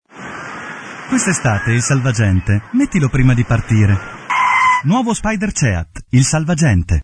Demo Audio Pubblicità Voiceover